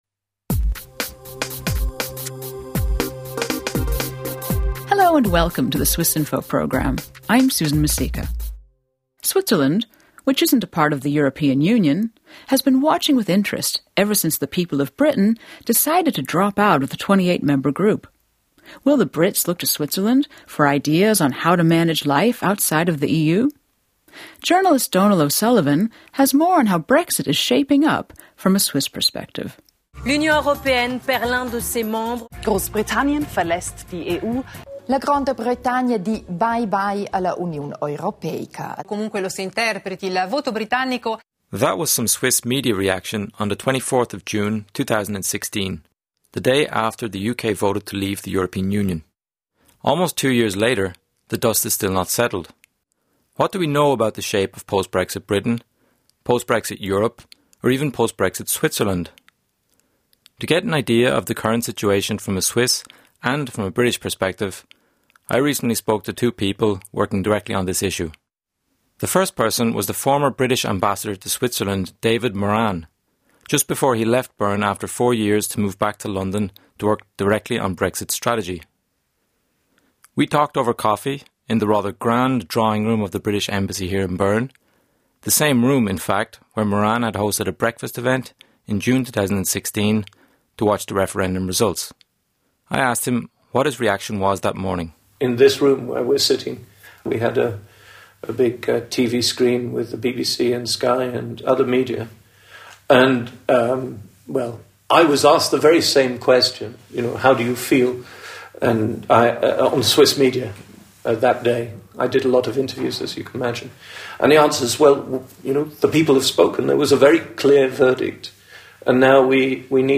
Keystone MP3 file Description Conversation about Brexit The knock-on effects of the 2016 Brexit vote still reverberate across Europe.